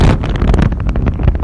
风声 " wind3
描述：风大风暴